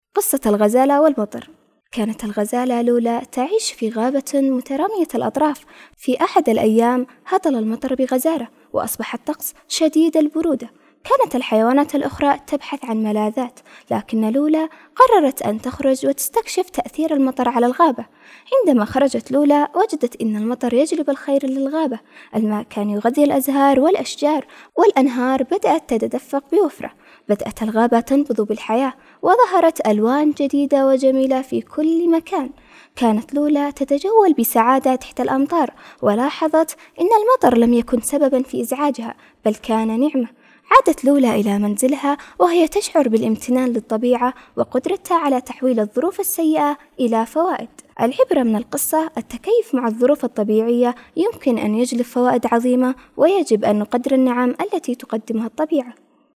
قصة قصيرة